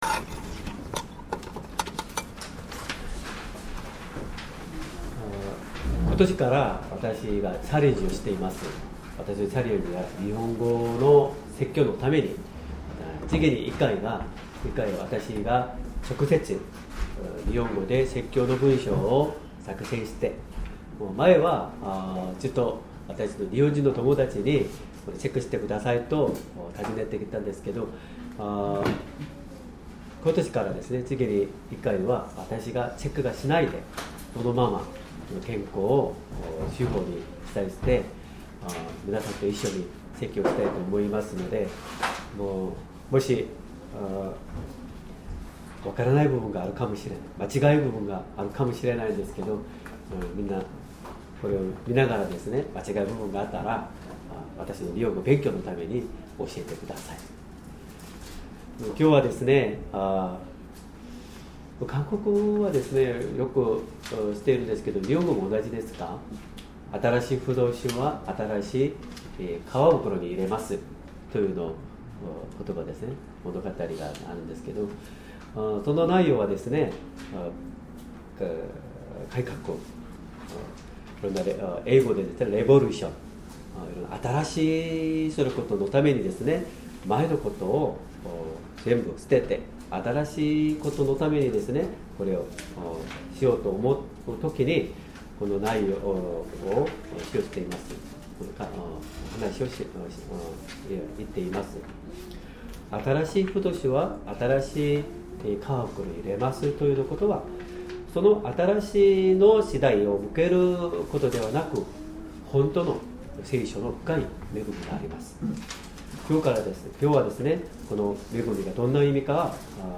Sermon
2025年3月30日 主日礼拝 説教 「新しいぶどう酒は新しい皮袋にいれます」 聖書 マタイの福音書 ９章９～１７節 9:9 イエスはそこから進んで行き、マタイという人が収税所に座っているのを見て、「わたしについて来なさい」と言われた。